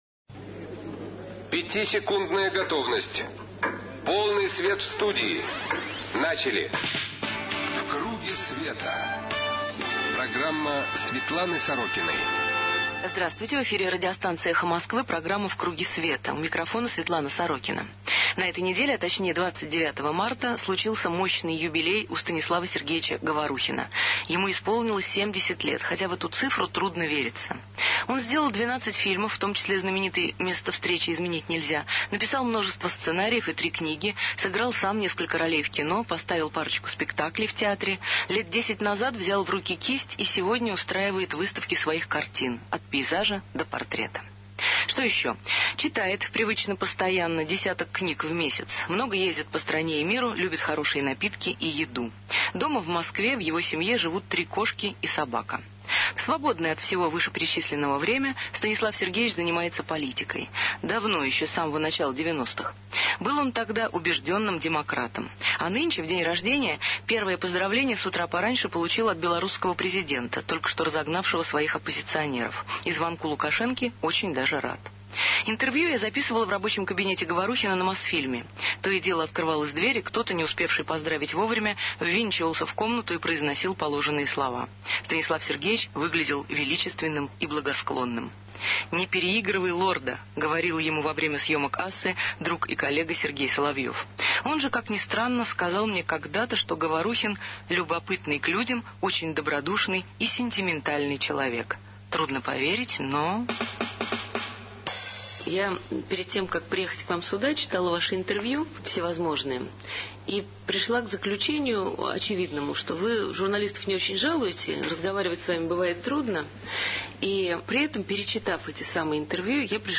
Интервью я записывала в рабочем кабинете Говорухина на Мосфильме. То и дело открывалась дверь, и кто-то, не успевший поздравить вовремя, ввинчивался в комнату и произносил положенные слова.